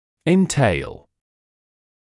[ɪn’teɪl][ин’тэйл]влечь за собой; вызывать